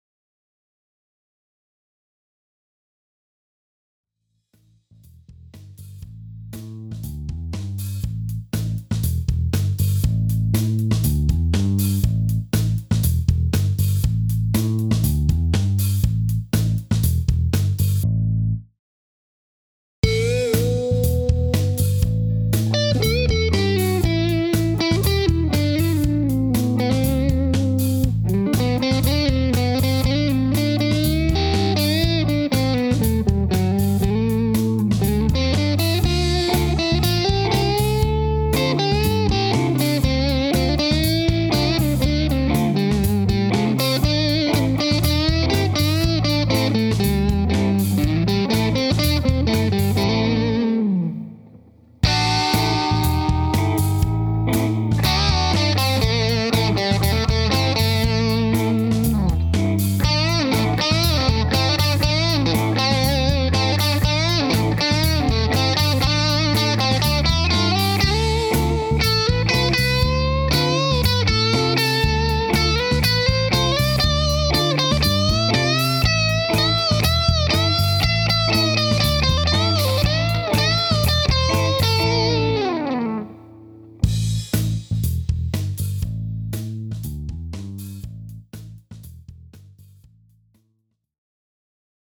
Cool Funk Lead
In the first part, I play in the neck position, then switch over to the bridge in the second part plus attack a lot more.
The net result is that overdrive tones tend to be much more tight and focused. BTW, the amp used here was an Aracom PLX18BB in its drive channel.